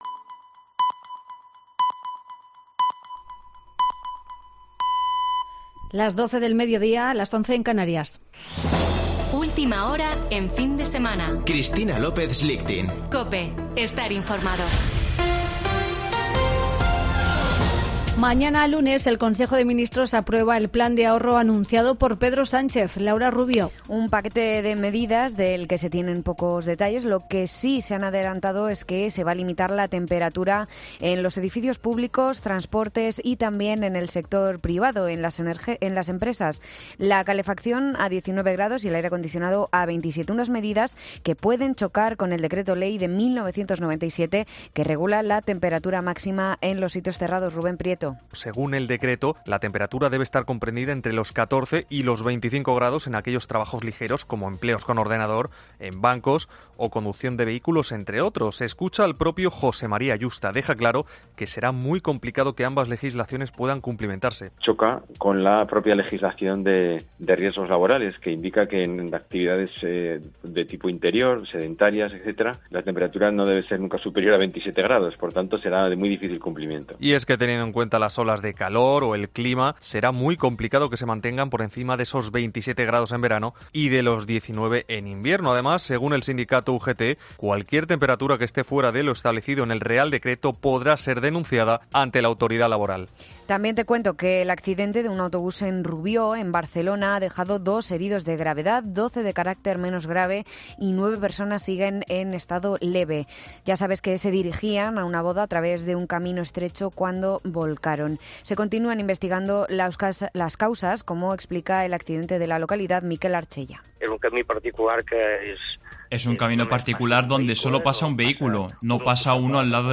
Boletín de noticias de COPE del 31 de julio de 2022 a las 12.00 horas